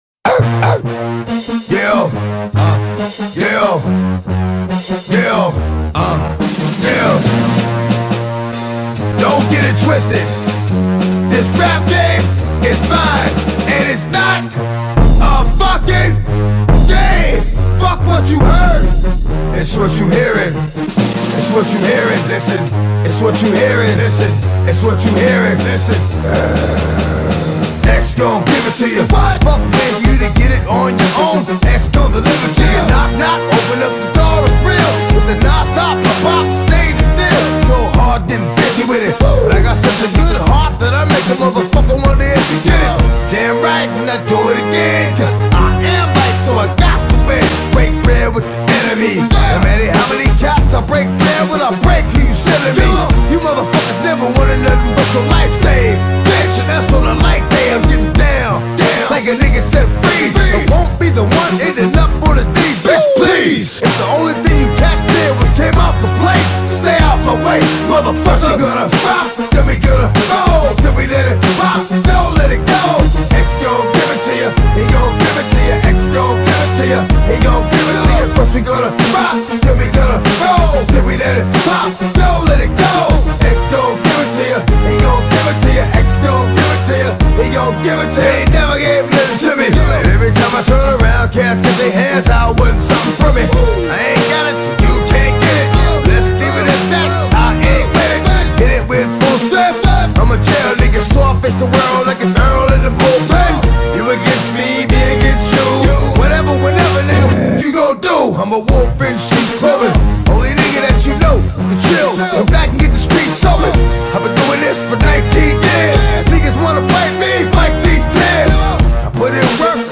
دانلود آهنگ ( داب استپ )